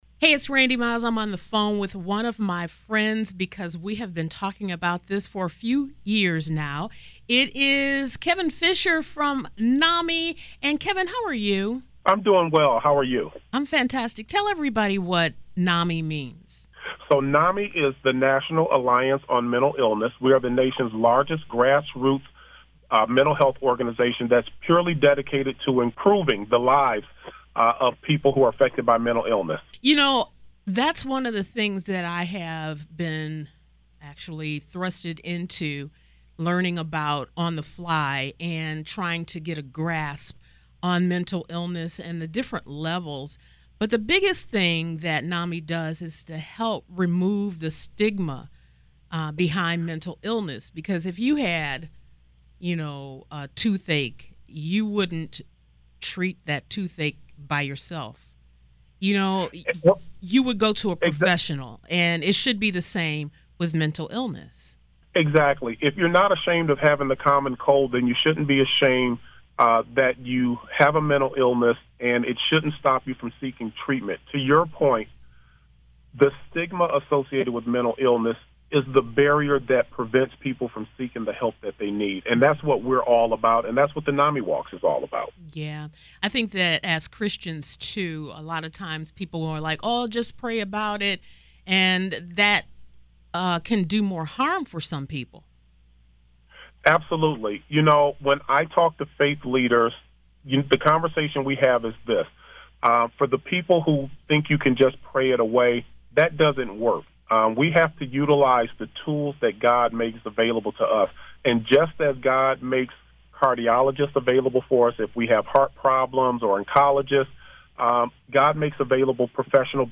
God Makes Professionals For Our Health & Behavioral Heath As Well [[Exclusive Interview]]